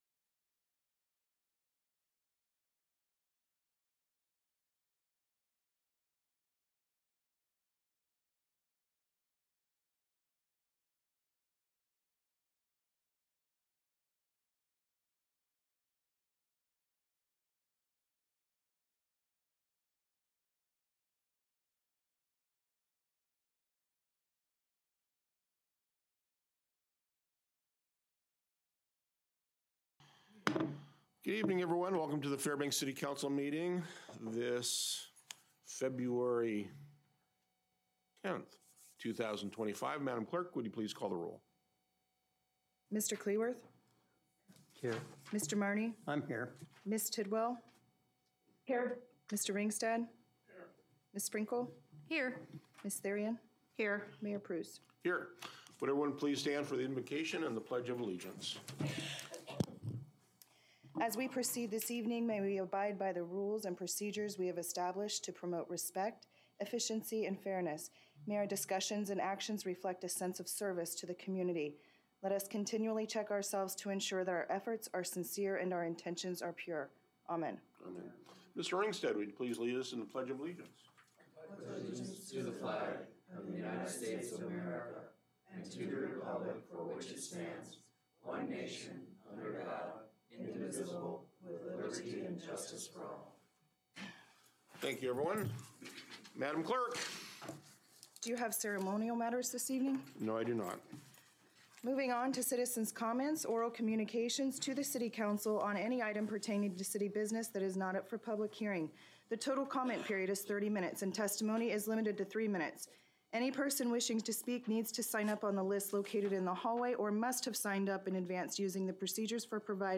Regular City Council Meeting